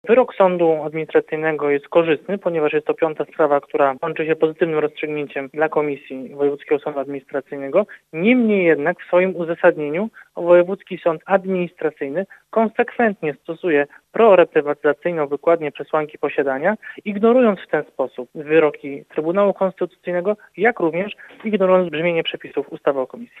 Choć wyrok dla komisji jest korzystny, Sebastian Kaleta, przewodniczący komisji weryfikacyjnej uważa, że błędne jest jego uzasadnienie: